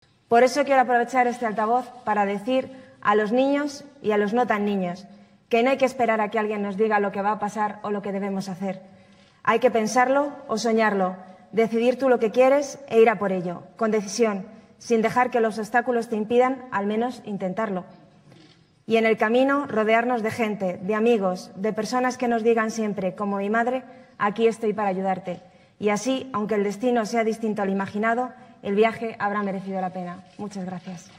En la ceremonia, la nadadora paralímpica fue una de los galardonados que tomó la palabra en representación de todos los demás durante la ceremonia y en su discurso relató su experiencia personal.